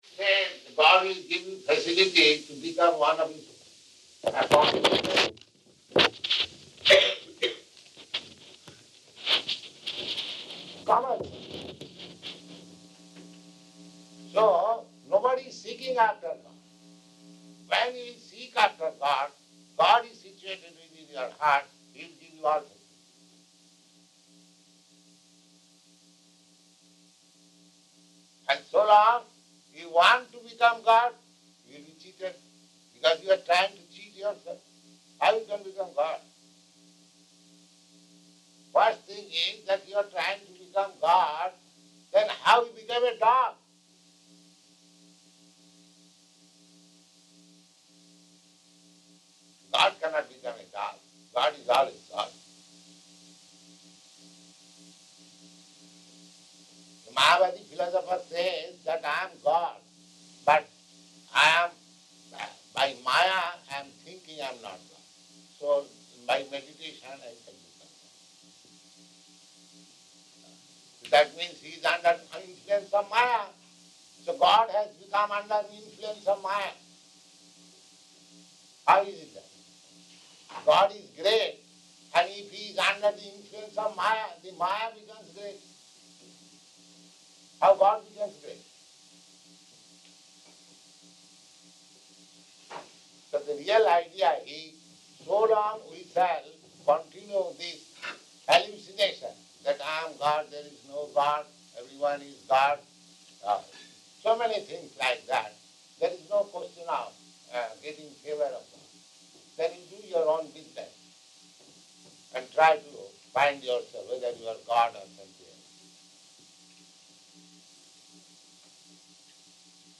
Lecture
Location: Boston